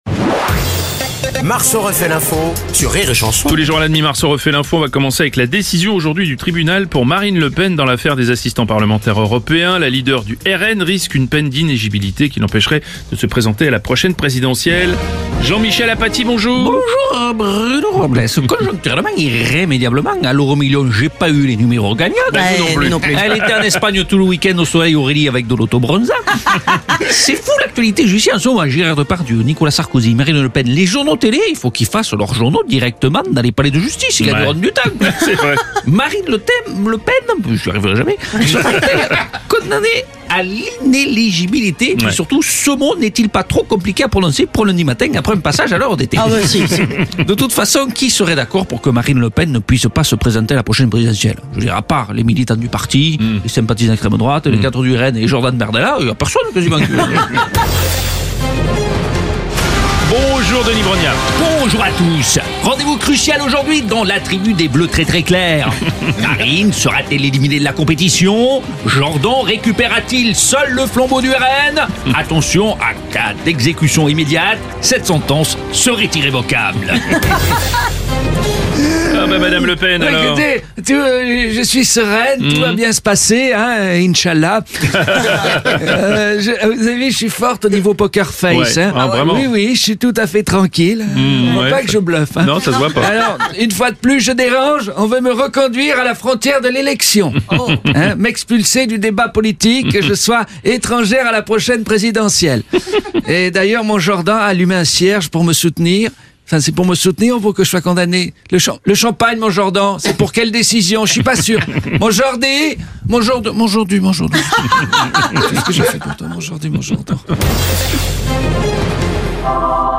… continue reading 1656 에피소드 # Comédie pour toute la famille # Divertissement # Rire et Chansons France # Chansons France # Tchat de Comédiens # Comédie